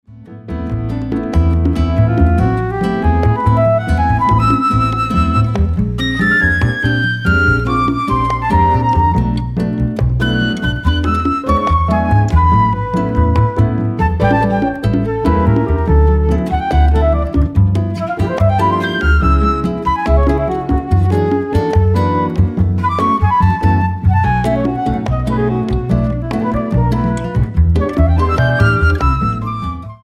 Tonart:Am ohne Chor